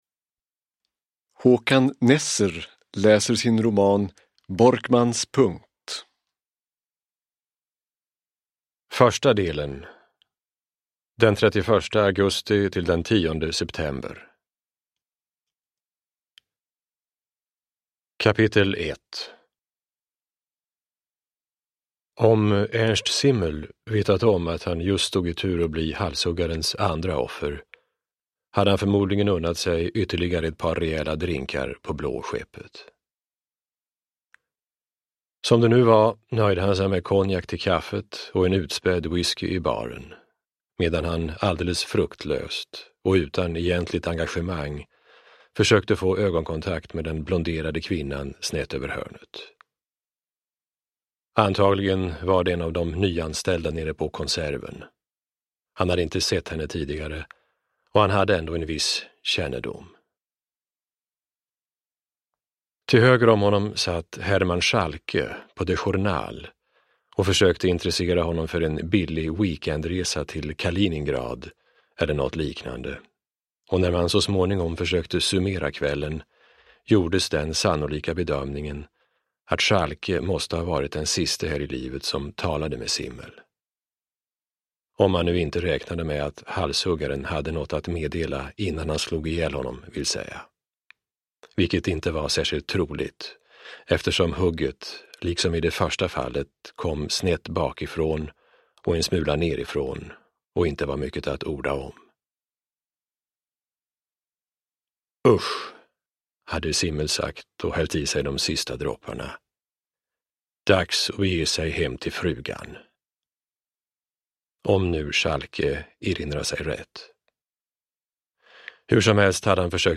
Uppläsare: Håkan Nesser
Ljudbok